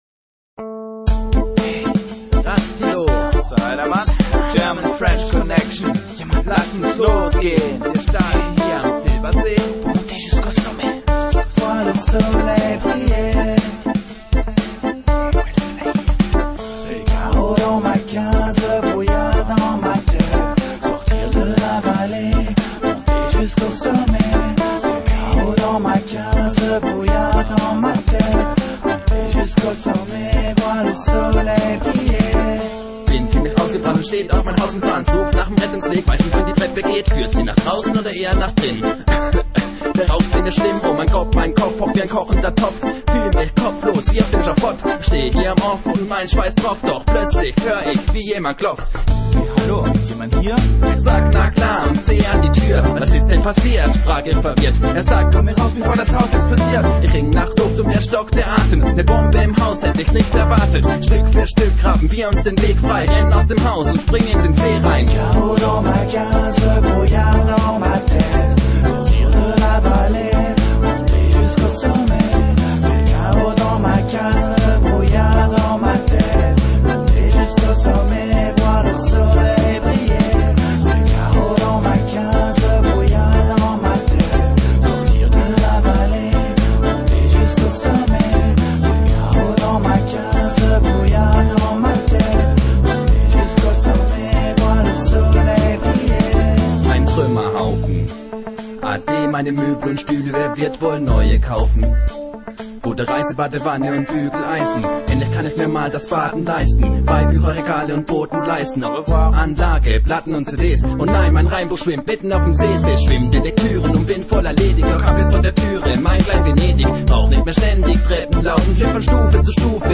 dub, reggae, hip hop and world music